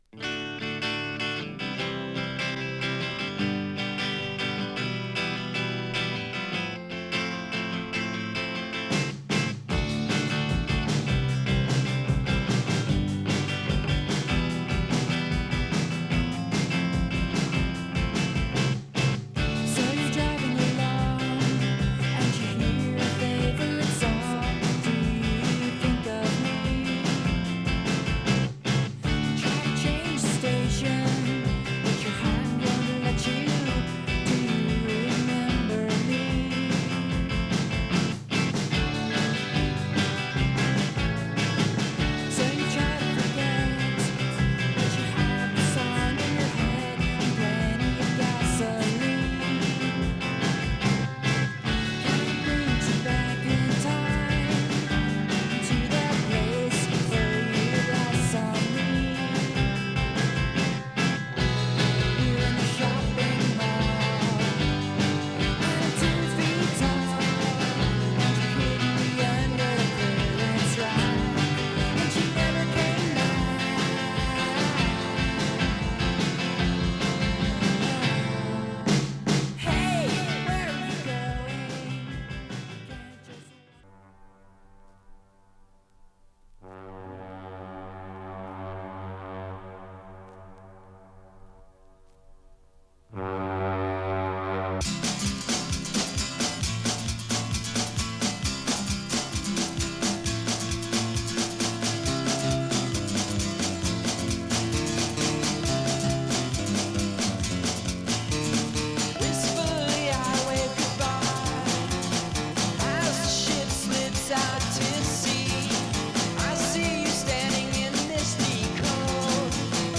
ギター掻き鳴らし蒼い歌を歌うインディー・ポップ好きマストな１枚。